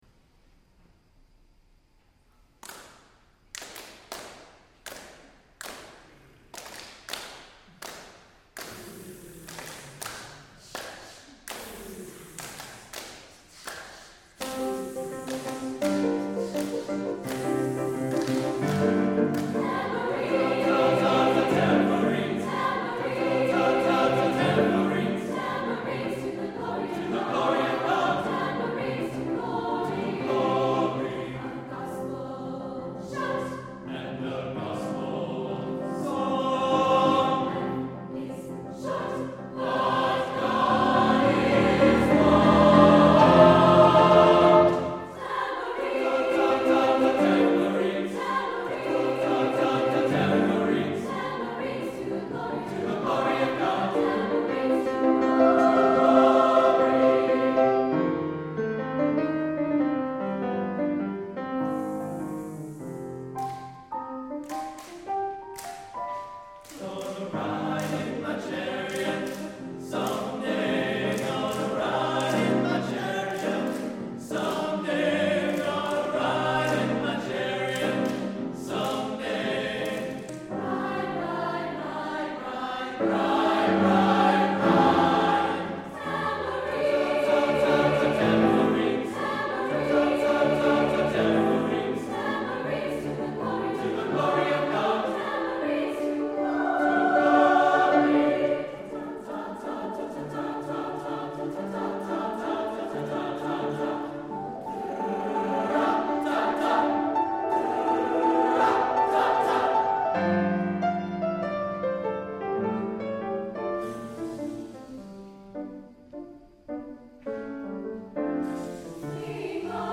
for SATB Chorus and Piano (2000)